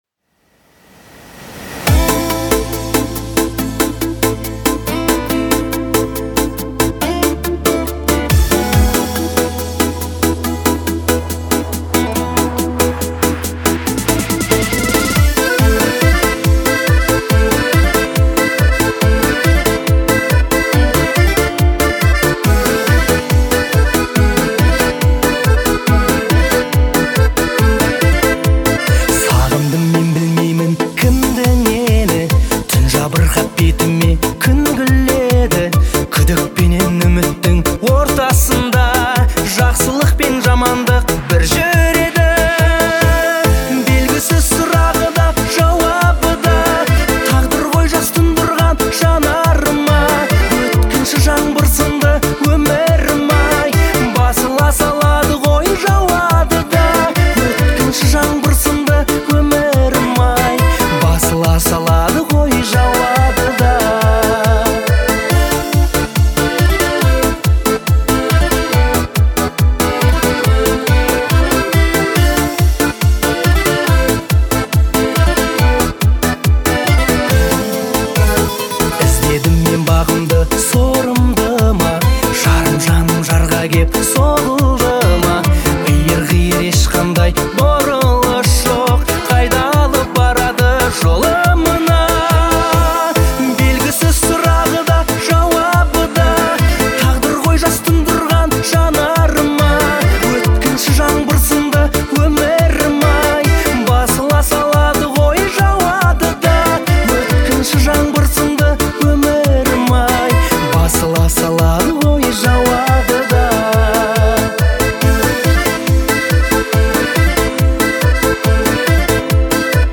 акцентируя внимание на вокале и акустических инструментах.